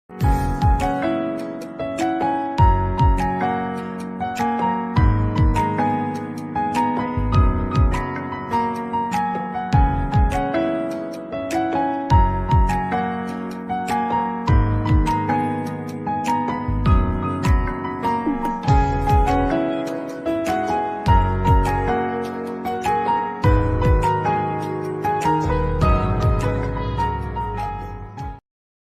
Category: Piano Ringtones